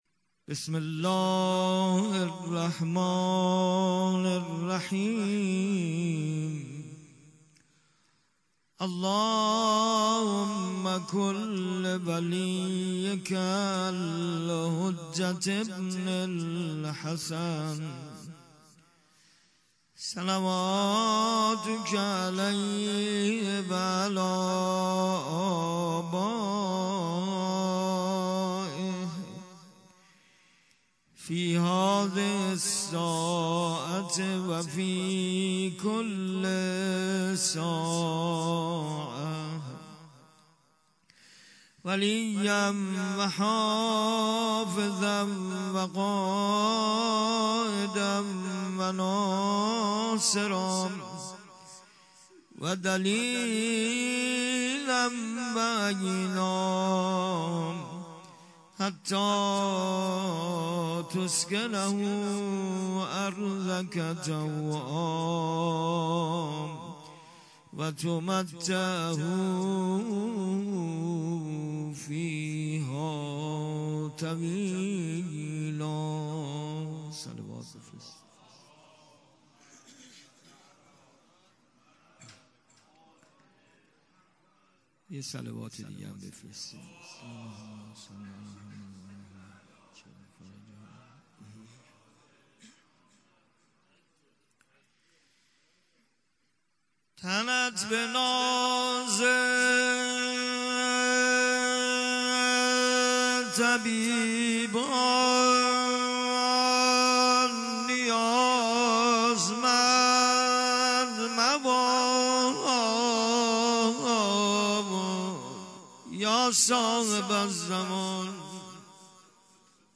فایل صوتی مراسم مناجات شعبانیه